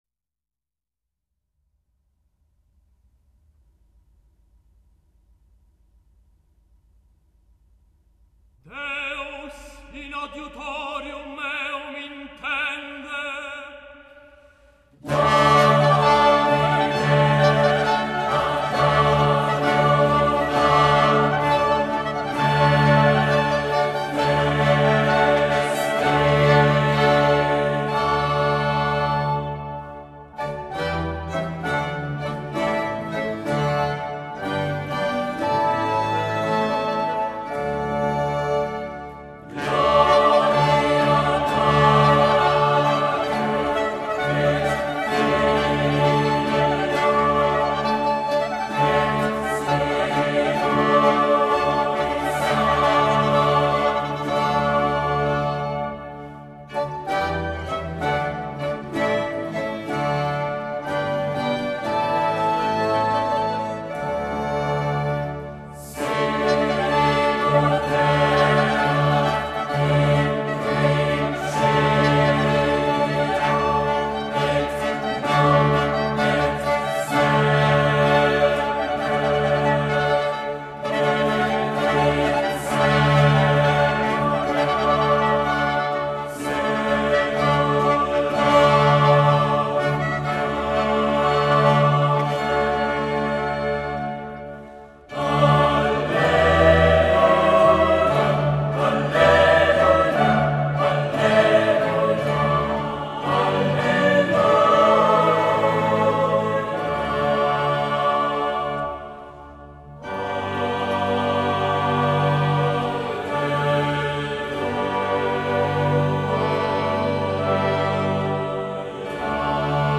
Monteverdi, Domine ad adiuvandum (responsorio dal Vespro 1610).mp3